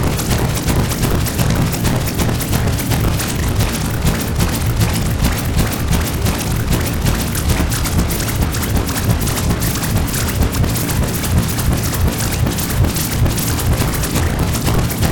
organic toy texture fractured.ogg
Original creative-commons licensed sounds for DJ's and music producers, recorded with high quality studio microphones.